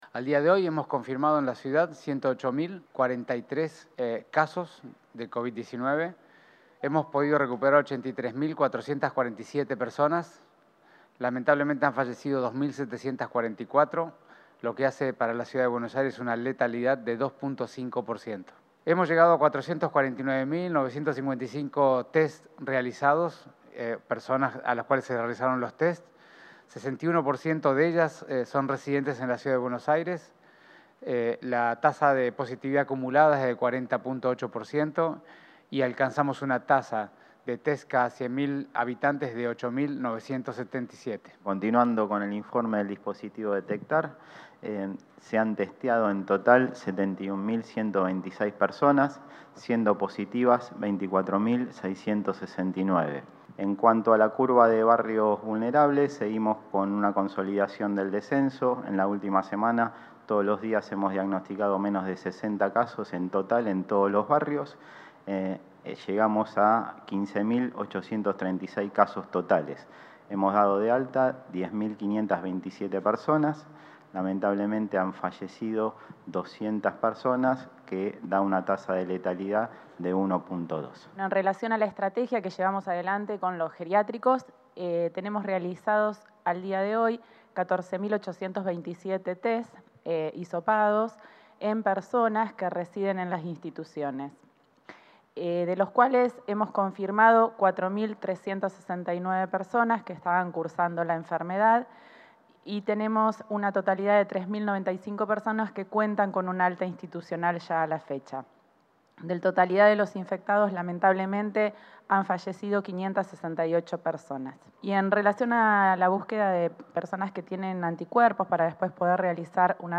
Participaron el ministro de Salud porteño, Fernán Quirós; el subsecretario de Atención Primaria, Gabriel Battistella, y la directora general de Planificación Operativa, Paula Zingoni.